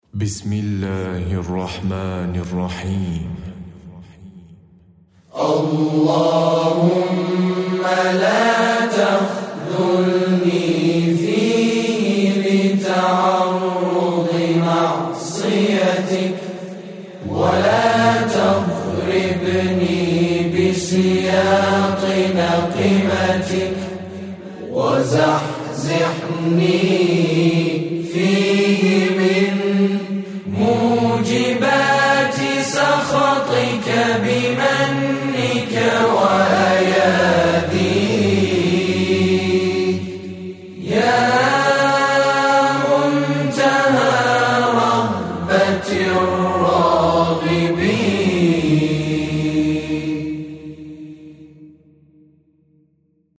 نماهنگ و سرود رسمی و معنوی